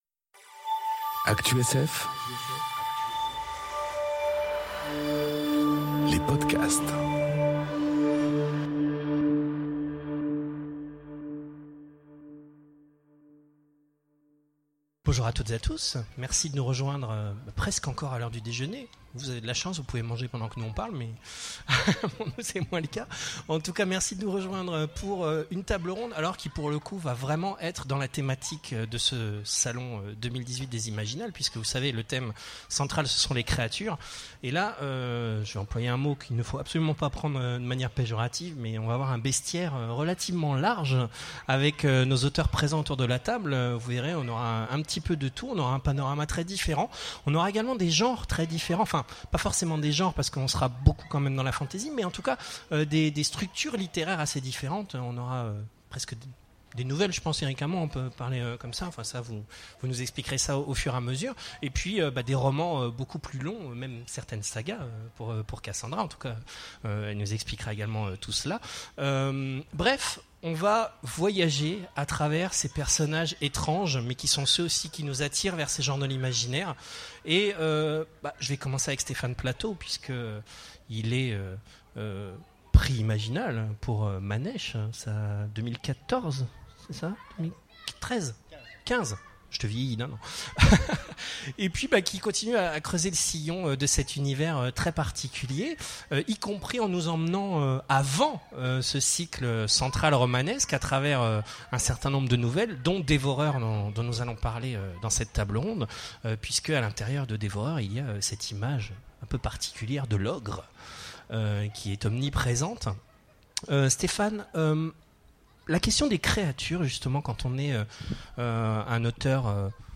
Conférence Métamorphes, berserkers, demi-dieux... Et autres créatures imaginaires enregistrée aux Imaginales 2018